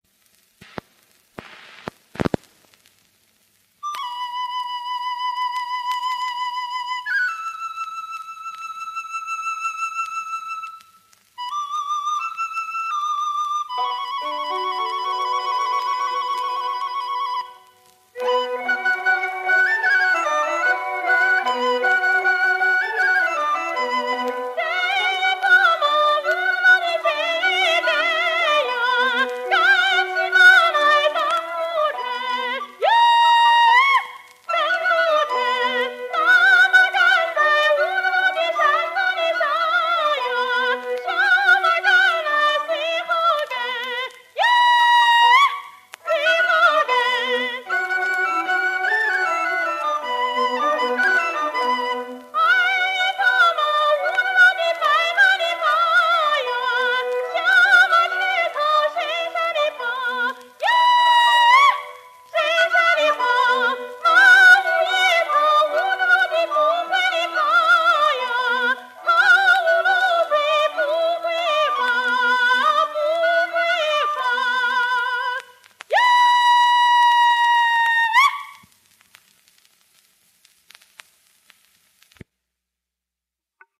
[25/12/2021]女高音歌唱家马玉涛50年代演唱的云南民歌《放马山歌》